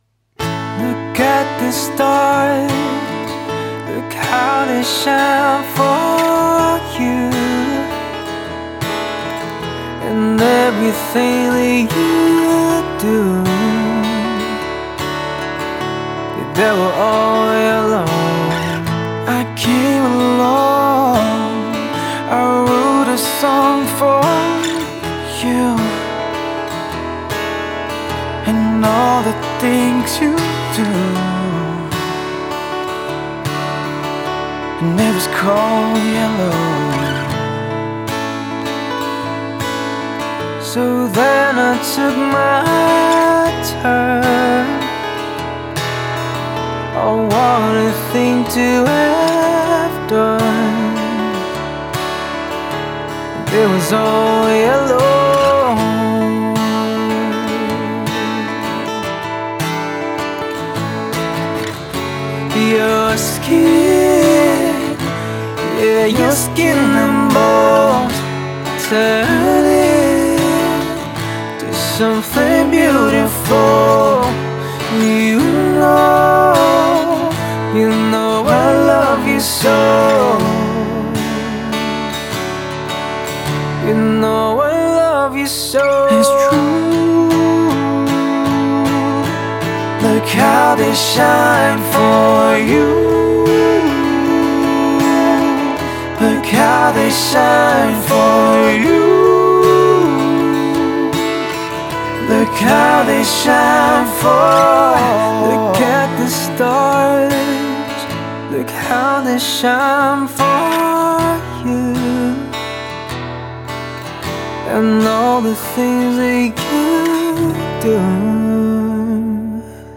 Dual Vocals | Dual Guitars | Looping | DJ | MC